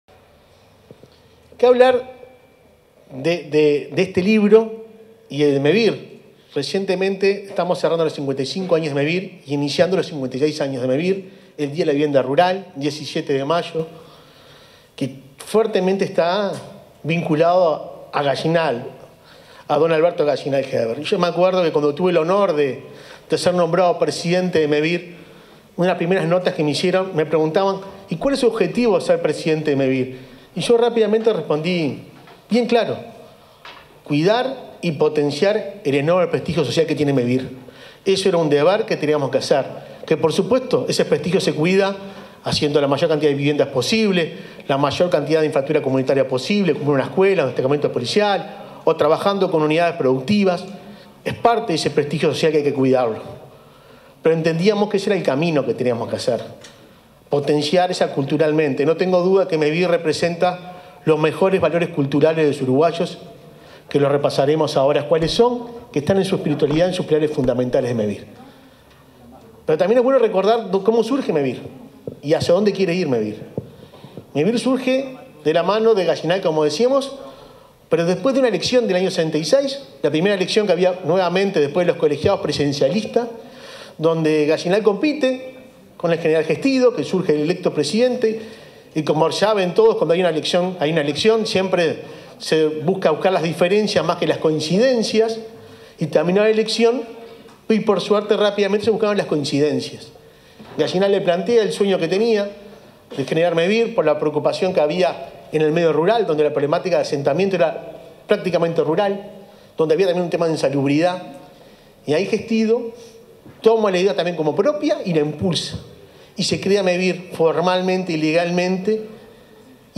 Palabras del presidente de Mevir, Juan Pablo Delgado